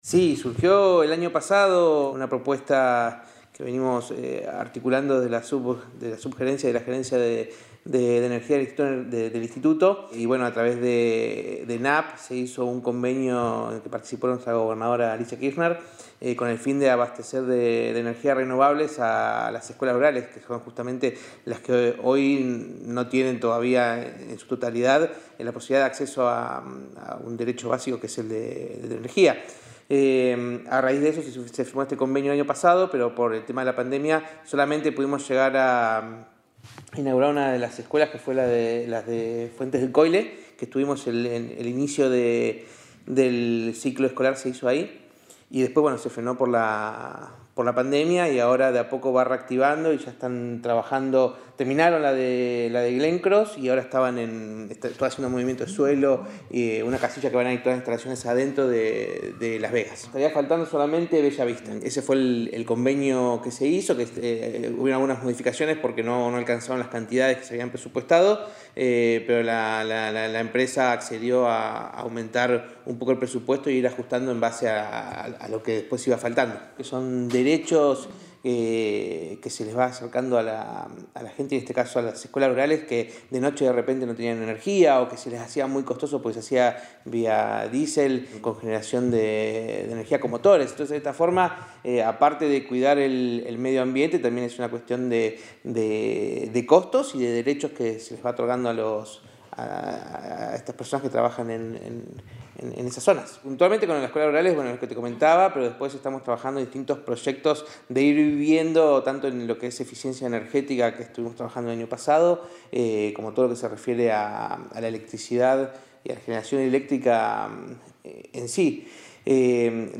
Esta mañana el presidente del Instituto de Energía Matías Kalmus, se refirió a la continuidad del proyecto que permite dotar de energía las 24 horas a las Escuelas Rurales de zona sur.